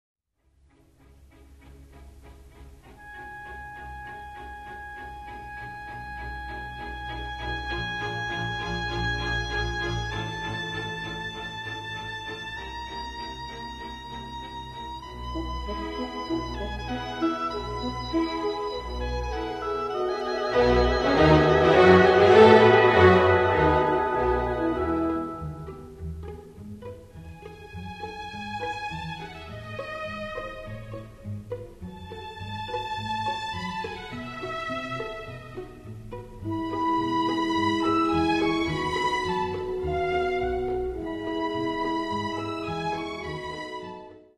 Каталог -> Інше -> Relax-piano, музична терапія